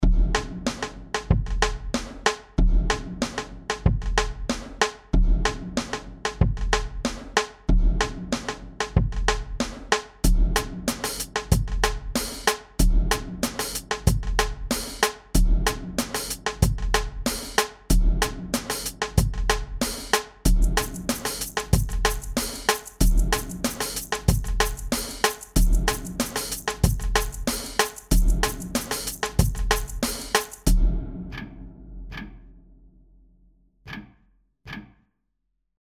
Es geht natürlich auch schöner, beispielsweise bei diesem Groove aus der Kategorie Filmscore & TV, Name „April 1“ aber sicher kein Aprilscherz. Es wird ein Tempo von 94 BPM empfohlen (an das man sich natürlich nicht halten muss.